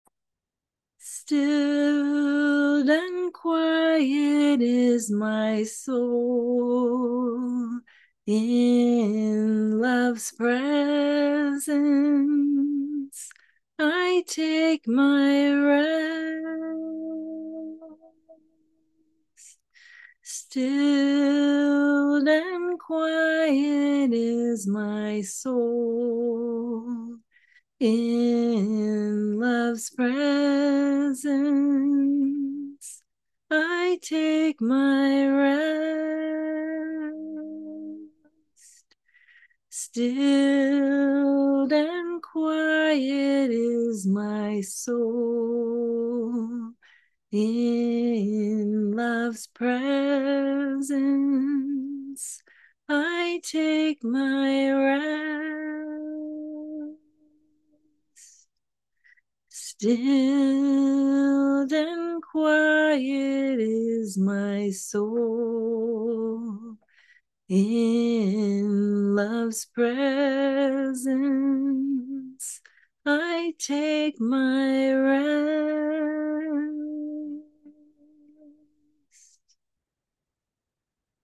Chant: Listen, listen wait in silence listening; for the one from whom all mercy flows (by The Oriental Orthodox Order in the West)